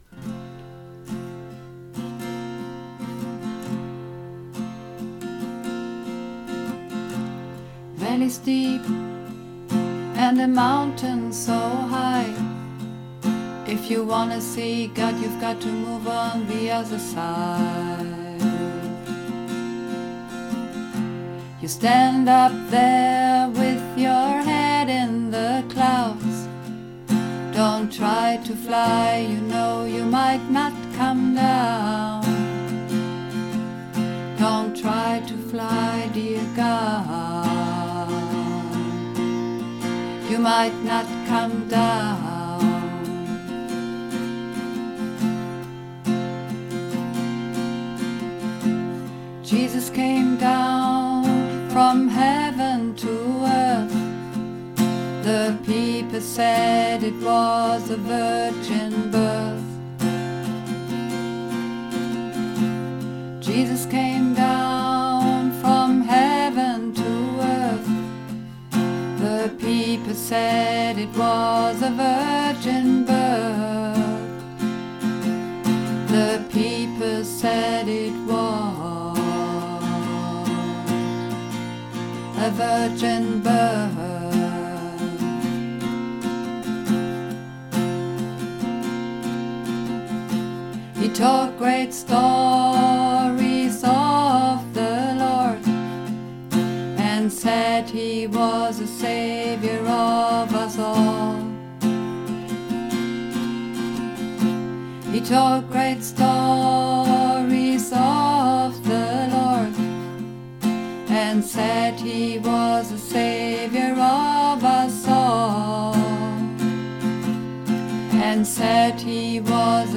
Übungsaufnahmen - Hymn
Runterladen (Mit rechter Maustaste anklicken, Menübefehl auswählen)   Hymn (Bass und Männer)
Hymn__2_Bass_Maenner.mp3